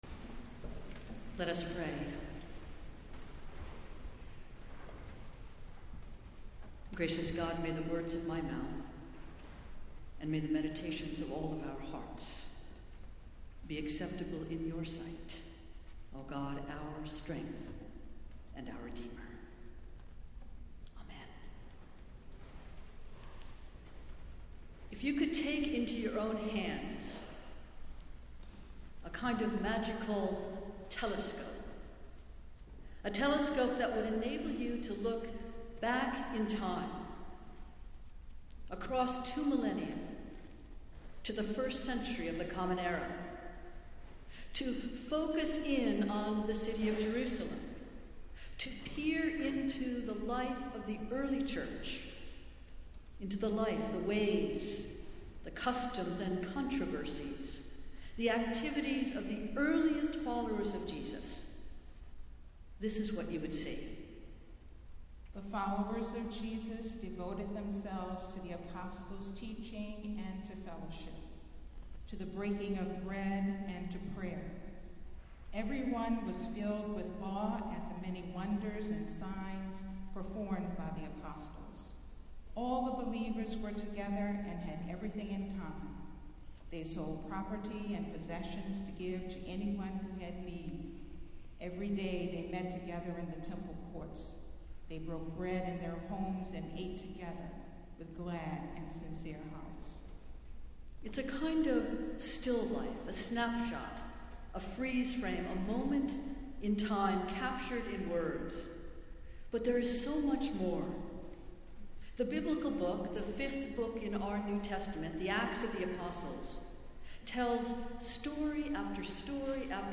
Festival Worship - Second Sunday after Pentecost